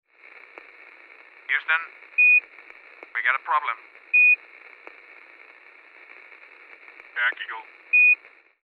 Astronauta llamando a la base central
Sonidos: Especiales
Sonidos: Voz humana
Receptor de radio